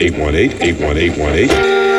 120BPMRAD7-L.wav